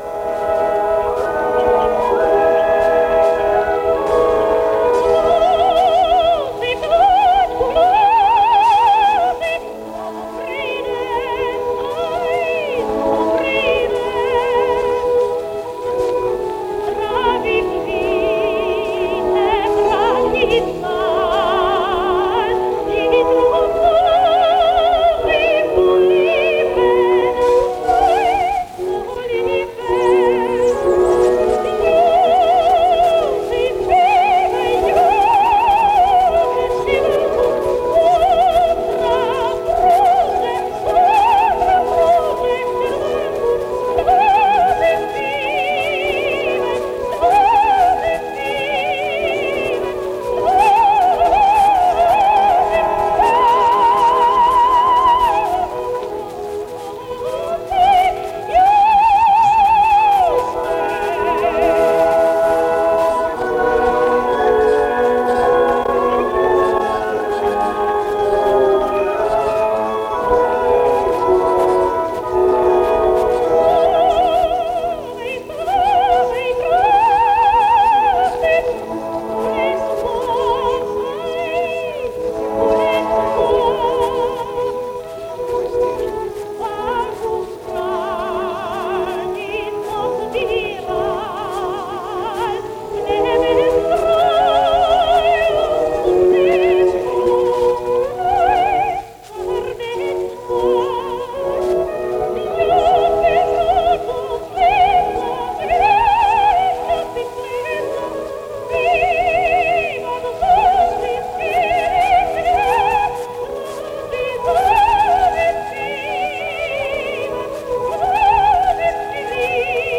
Её имя сияло на европейской музыкальной сцене начала XX века. Христину Морфову называли «болгарским соловьем», ведь потрясающий голос с диапазоном в три октавы позволял ей выступать в репертуарах колоратурного, лирического и драматического сопрано.